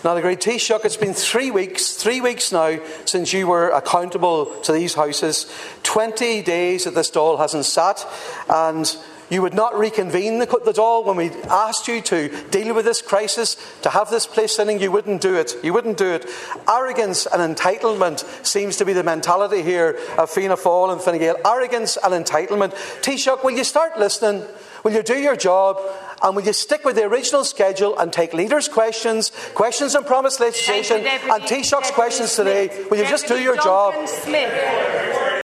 When a revised Order of Business was proposed by Chief Whip Mary Butler, Donegal TD and Sinn Fein Whip Padraig MacLochlainn was one of the opposition TDs who objected………